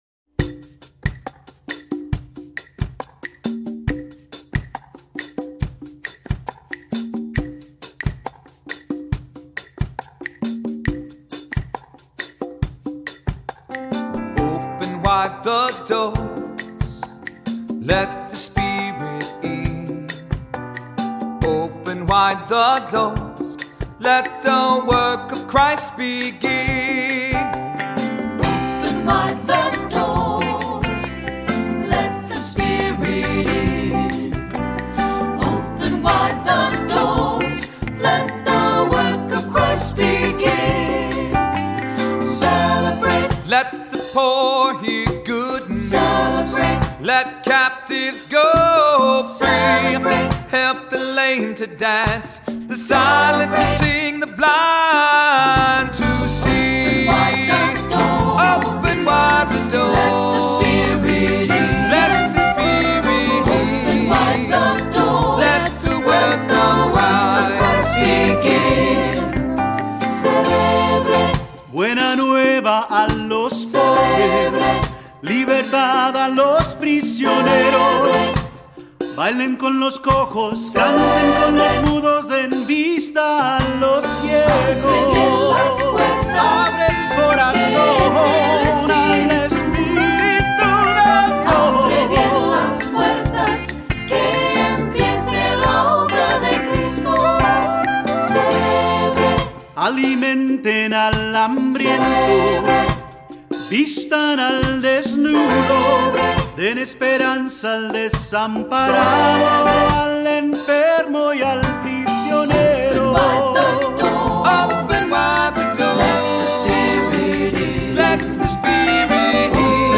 Voicing: Assembly,SATB,Soloist or Soloists